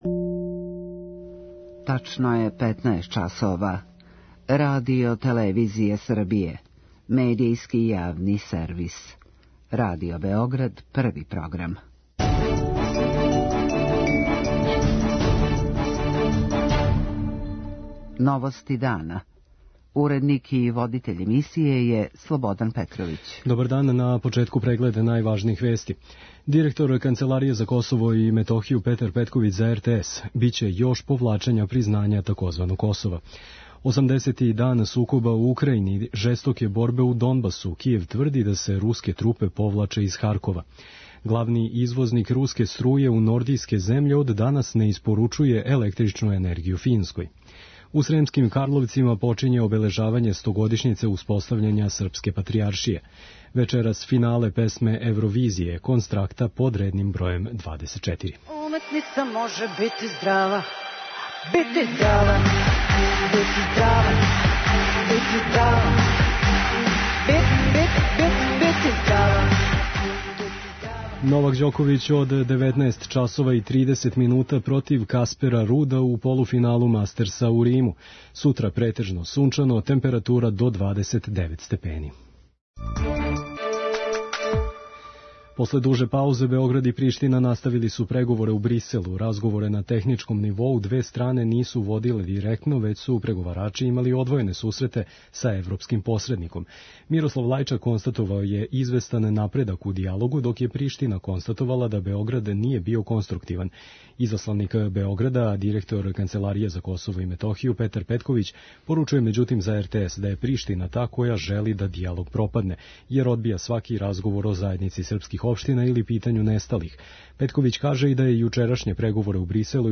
централна информативна емисија